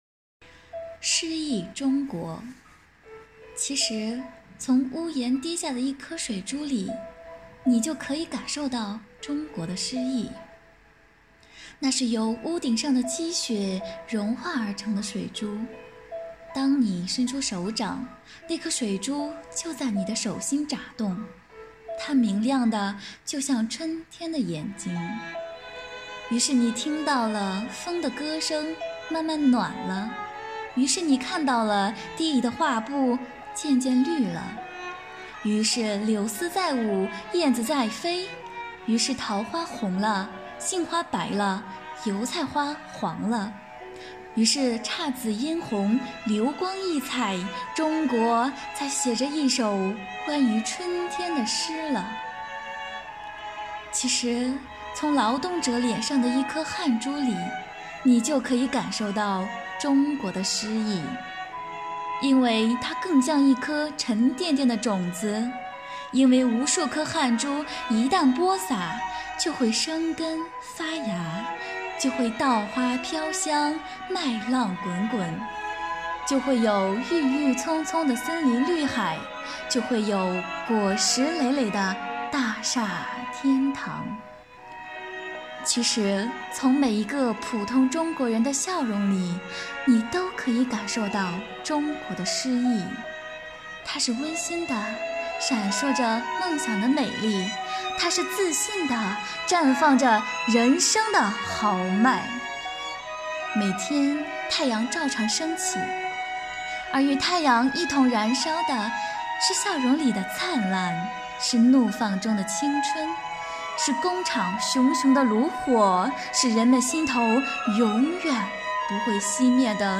在五一劳动节、五四青年节来临之际，市纪委监委宣传部、机关党委组织青年干部，以“奋进新征程筑梦新时代”为主题，以朗诵为载体，用诗篇来明志，抒发对祖国的热爱、对梦想的执着、对青春的礼赞。